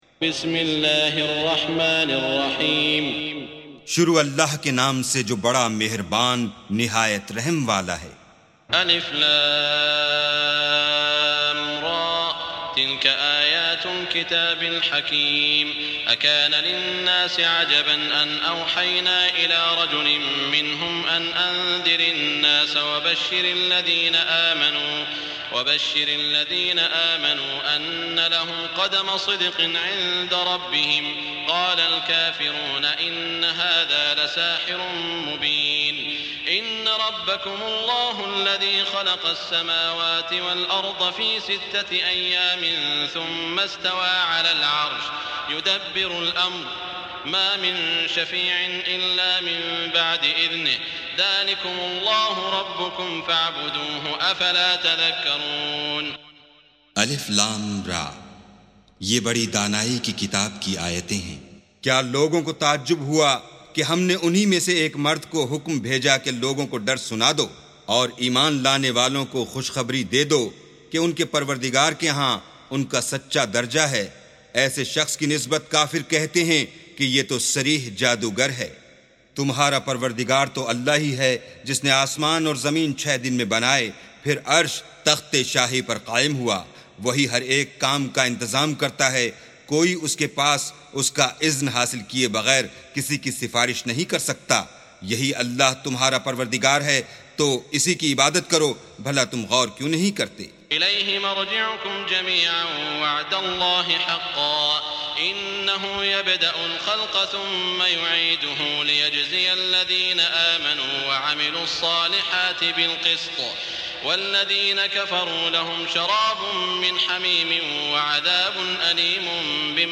استمع أو حمل سُورَةُ يُونُسَ بصوت الشيخ السديس والشريم مترجم إلى الاردو بجودة عالية MP3.
سُورَةُ يُونُسَ بصوت الشيخ السديس والشريم مترجم إلى الاردو